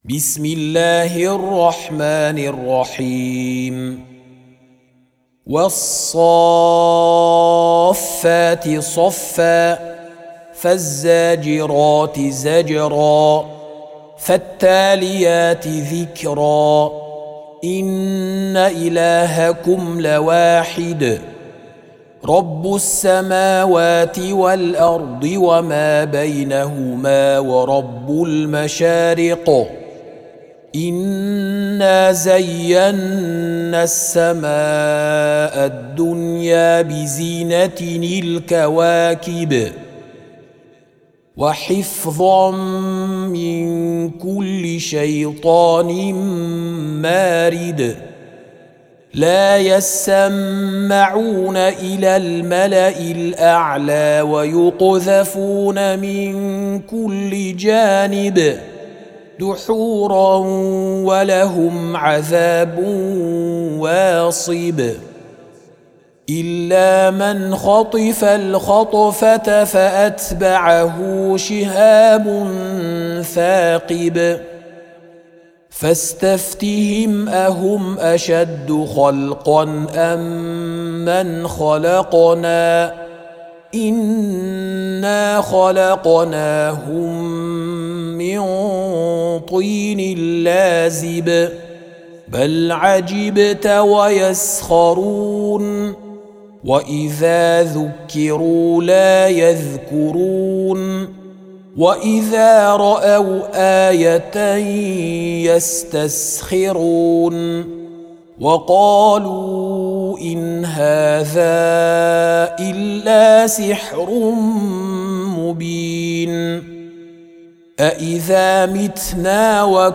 Қуръони карим тиловати, Қорилар. Суралар Qur’oni karim tilovati, Qorilar.